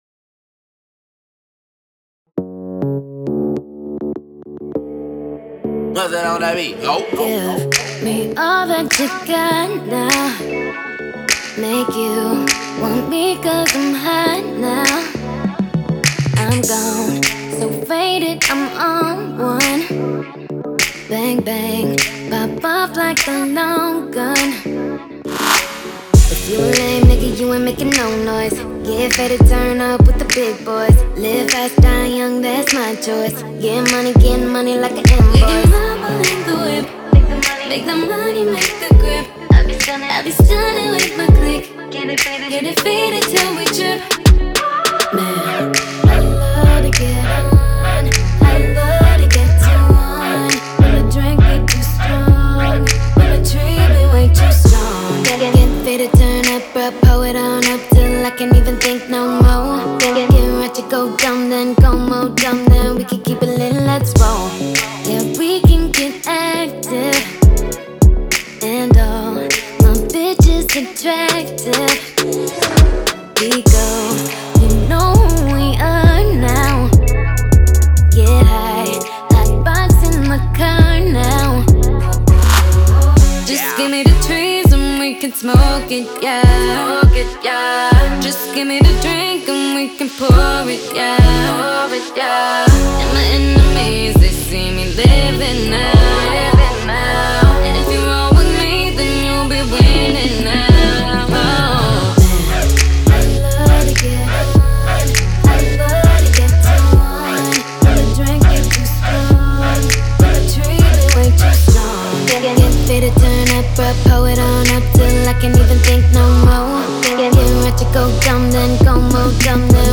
BPM101
a bop in need of a simfile